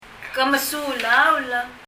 Ke mesulaul? mesulaul [mɛsu:lául] Sleepy 眠い 英） Are you sleepy?